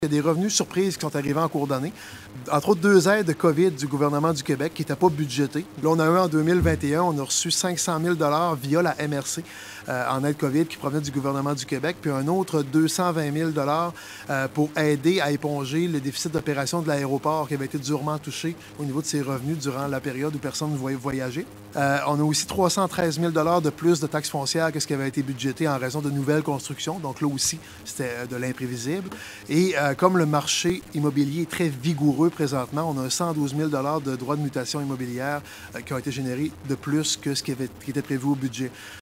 Le maire de Gaspé, Daniel Côté, précise les différents points de revenus qui expliquent cette situation financière très positive de la ville.